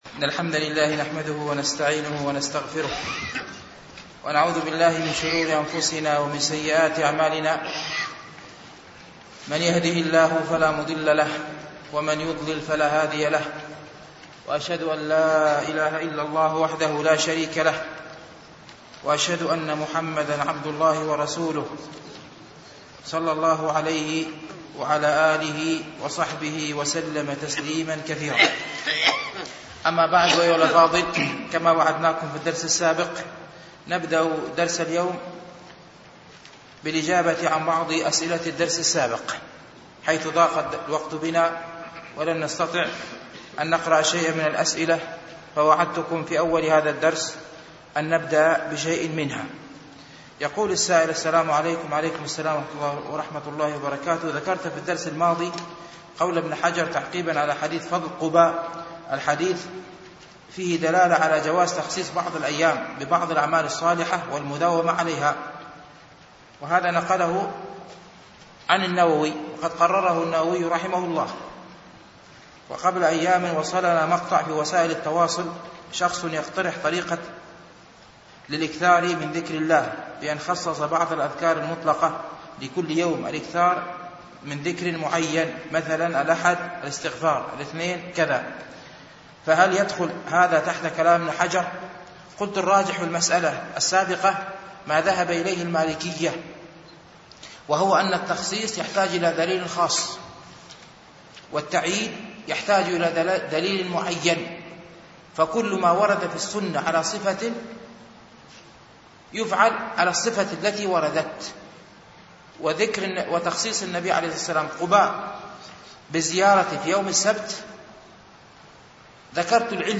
شرح رياض الصالحين ـ الدرس الحادي عشر بعد المئة